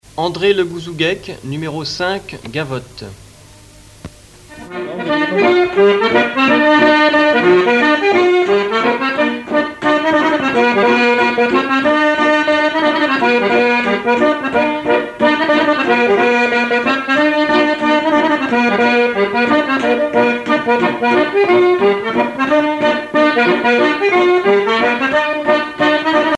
danse : gavotte bretonne
Pièce musicale éditée